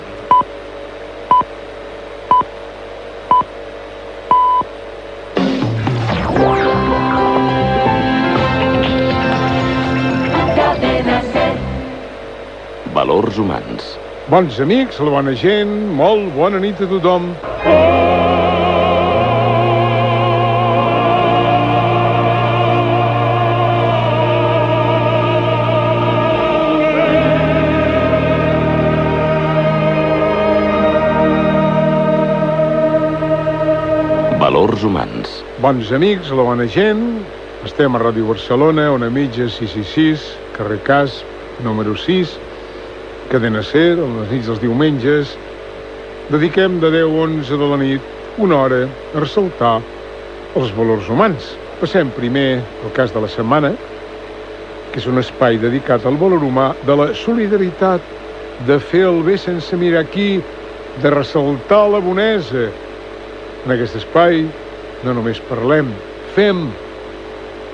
Identificació del programa, salutació, identificació, el cas de la setmana.
Religió